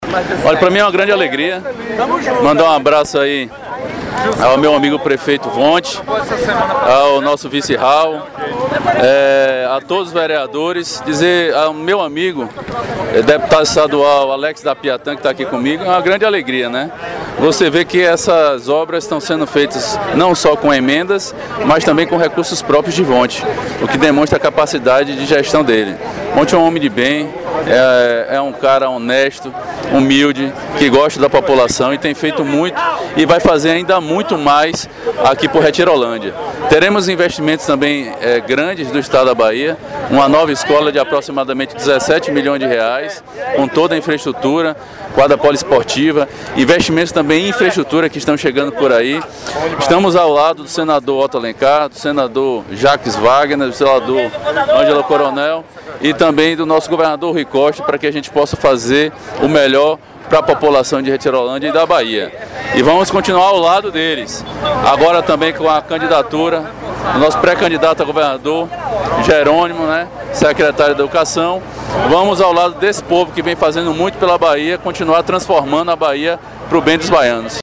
A equipe do Informe Bahia entrevistou o deputado federal Otto Filho, o mesmo destacou o bom trabalho que o prefeito Vonte vem fazendo no município.